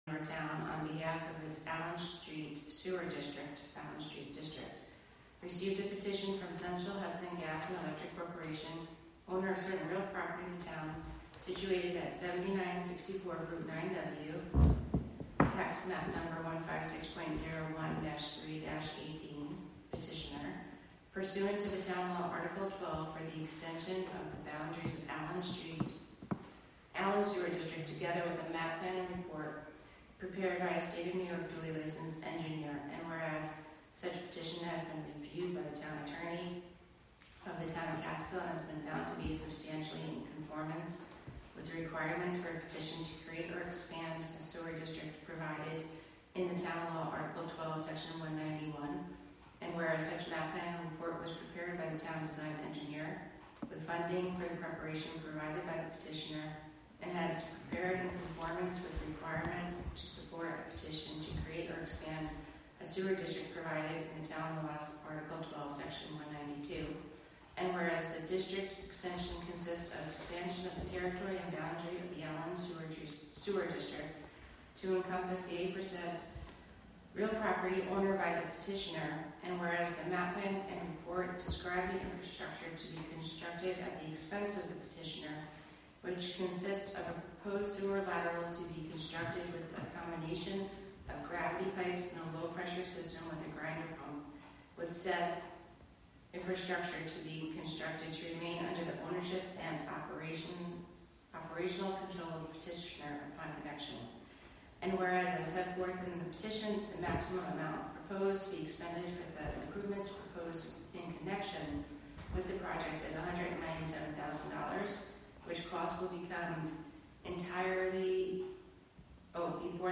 Live from the Town of Catskill: August 20, 2025 Catskill Town Board Committee Meeting (Audio)